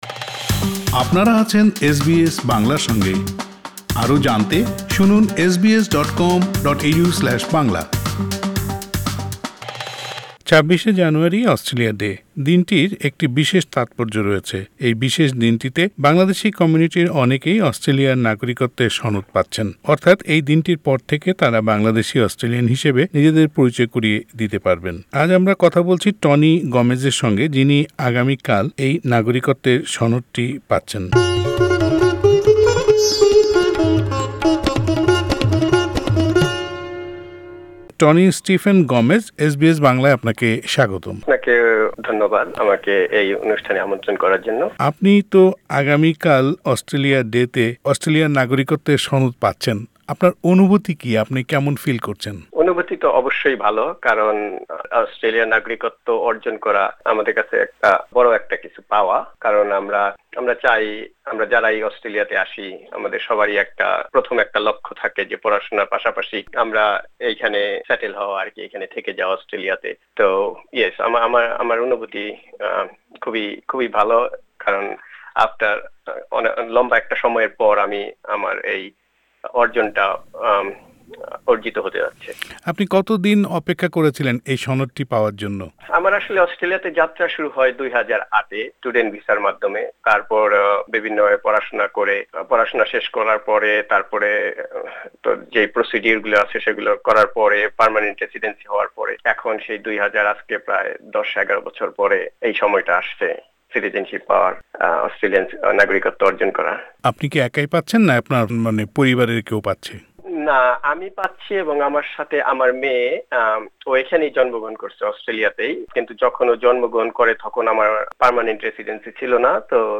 এস বি এস বাংলার সঙ্গে আলাপচারিতায় কথা প্রসঙ্গে তিনি অস্ট্রেলিয়া ডে নিয়ে তার অনুভূতির কথা জানালেন ।